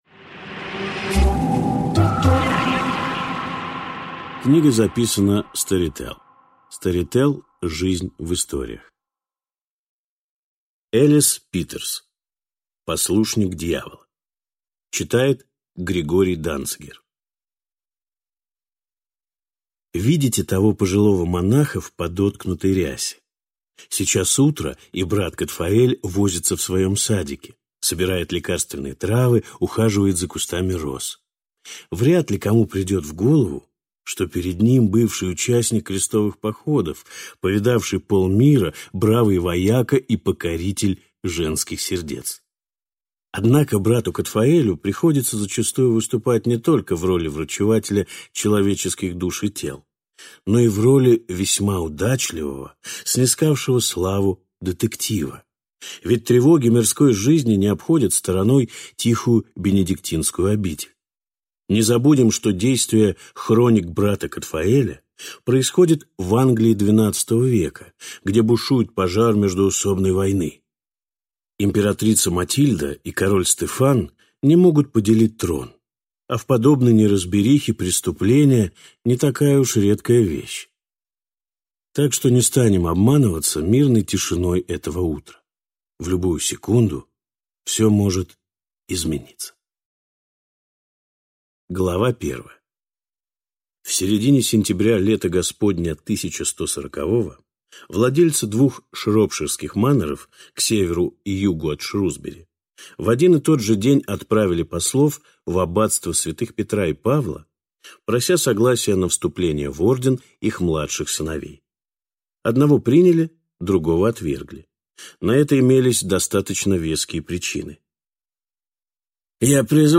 Аудиокнига Послушник дьявола | Библиотека аудиокниг